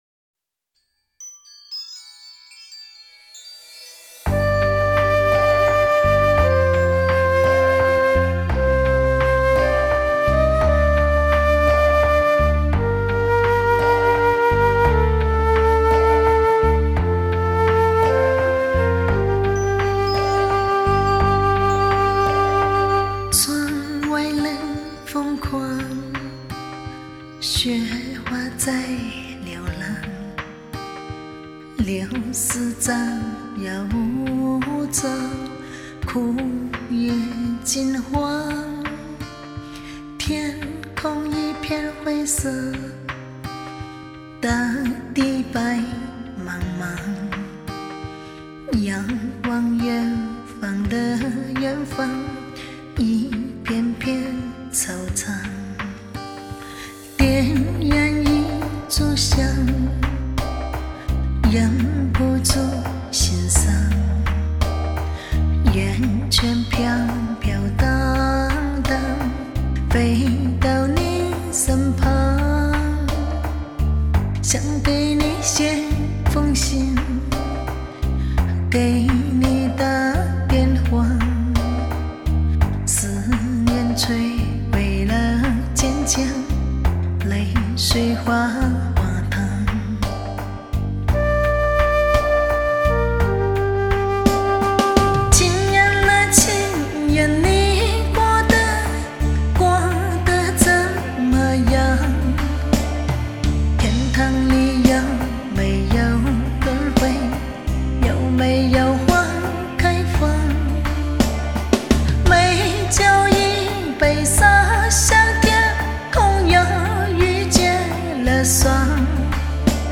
音色柔和甜美有磁性的嗓子